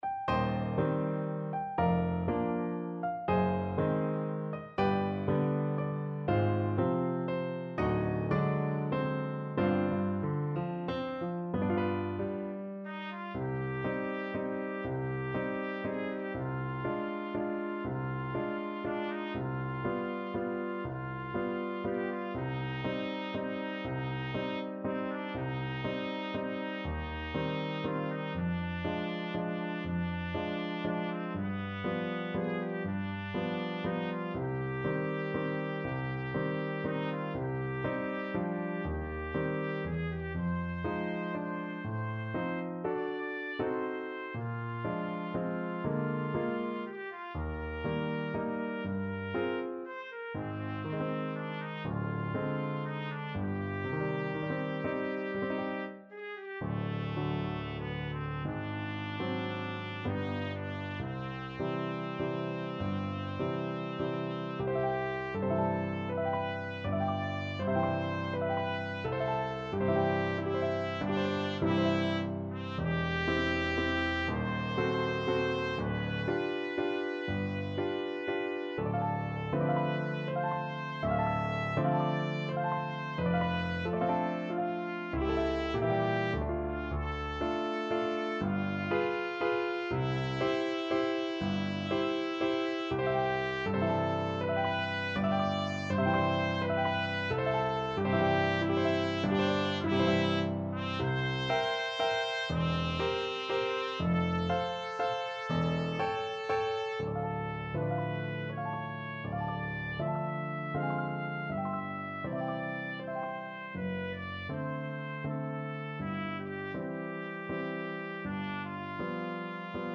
3/4 (View more 3/4 Music)
~ = 120 Lento
Classical (View more Classical Trumpet Music)